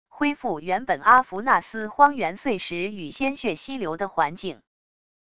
恢复原本阿弗纳斯荒原碎石与鲜血溪流的环境wav音频